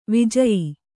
♪ vijayi